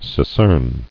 [se·cern]